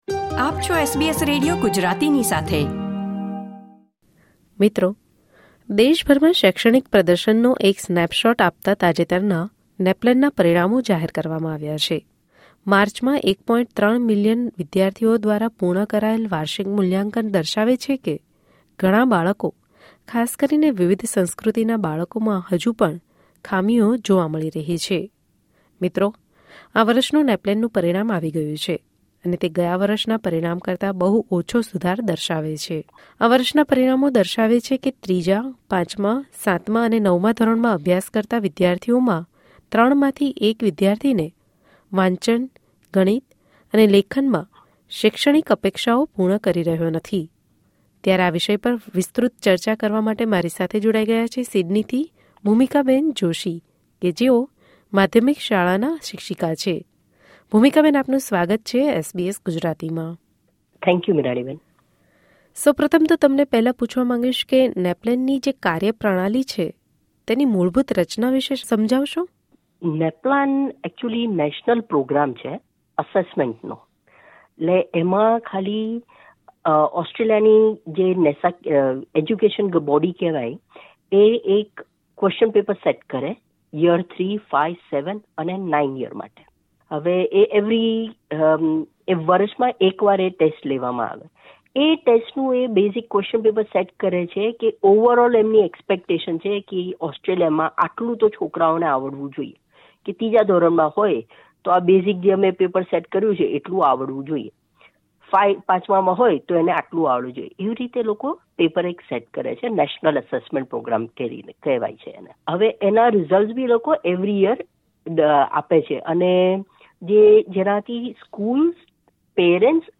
ત્યારે આ વિષય પર SBS Gujarati સાથે વાતચીત કરી હતી